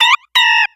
Cries
PANCHAM.ogg